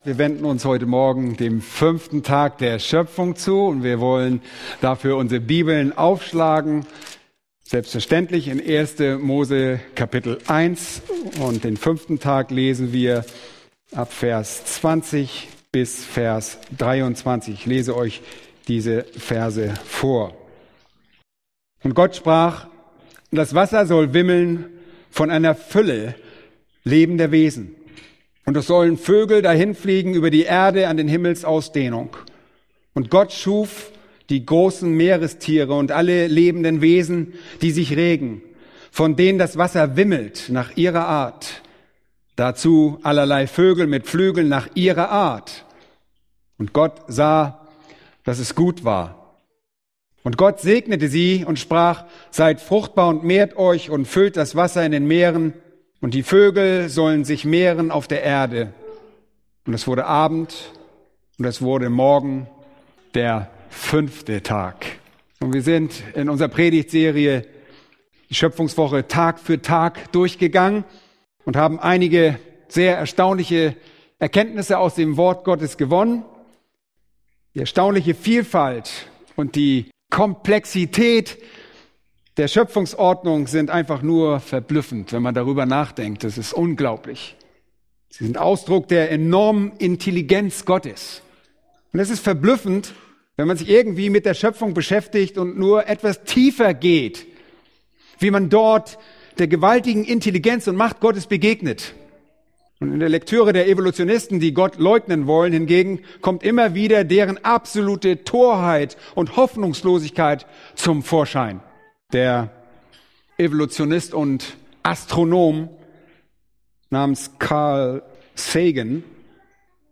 Predigten Übersicht nach Serien - Bibelgemeinde Barnim